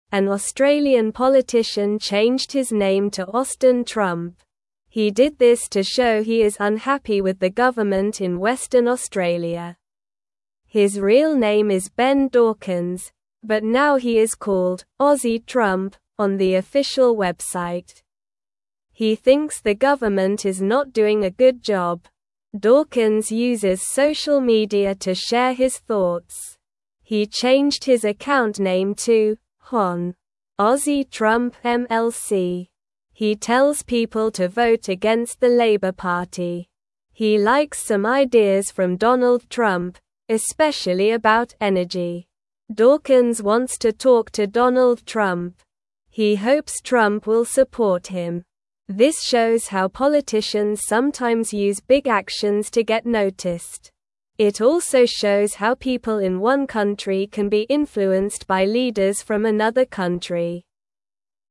Slow
English-Newsroom-Lower-Intermediate-SLOW-Reading-Man-Changes-Name-to-Austin-Trump-for-Attention.mp3